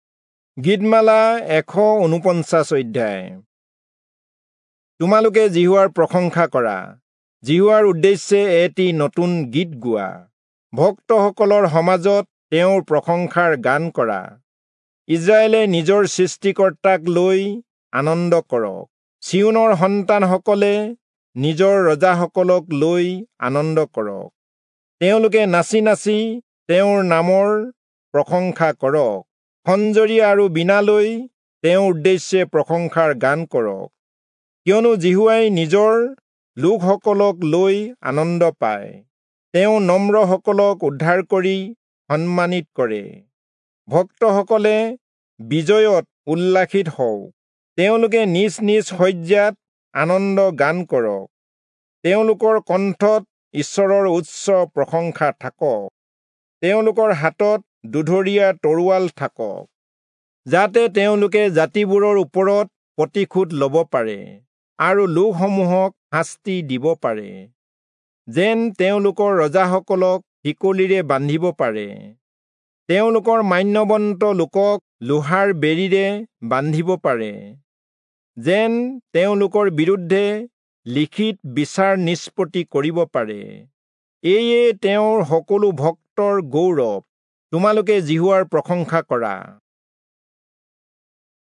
Assamese Audio Bible - Psalms 140 in Irvas bible version